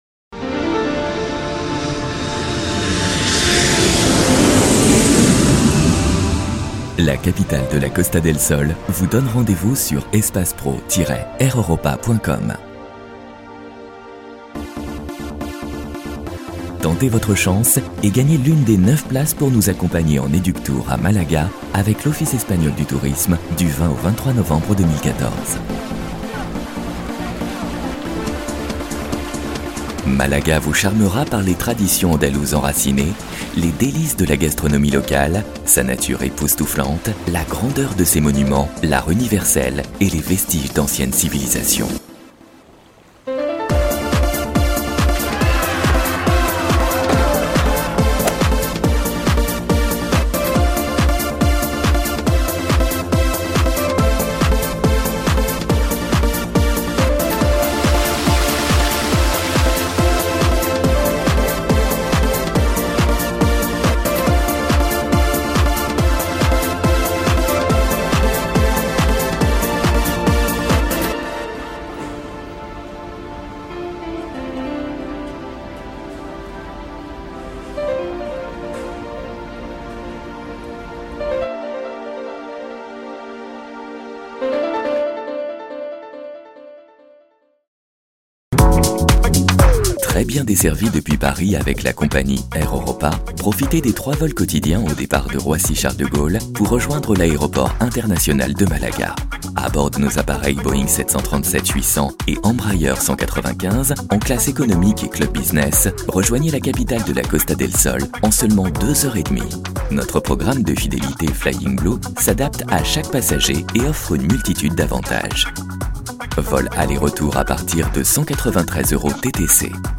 comedien voix off institutionnel - Comédien voix off
AIR EUROPA elegant, chaleureux